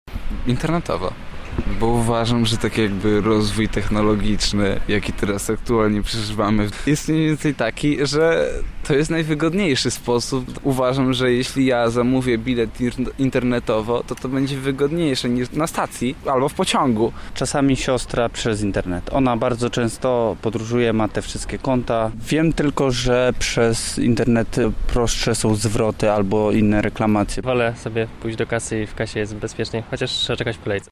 Zapytaliśmy mieszkańców naszego miasta w jaki sposób najczęściej nabywają bilety: